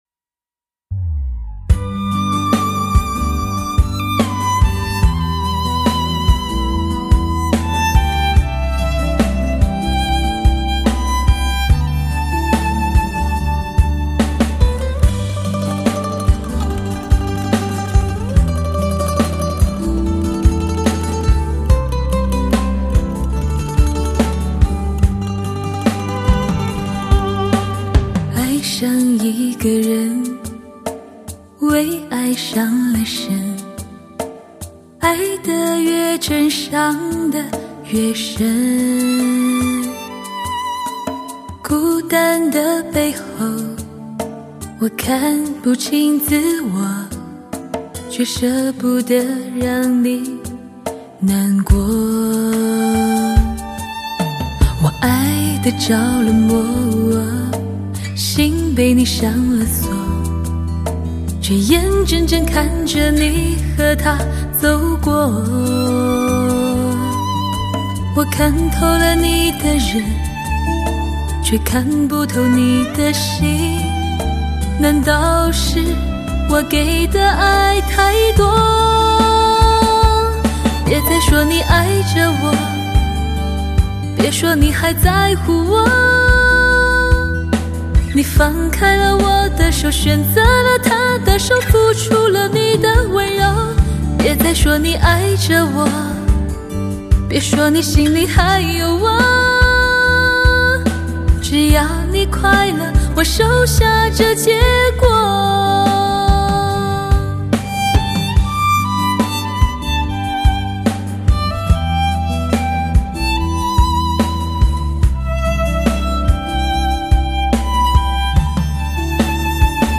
类型: 汽车音乐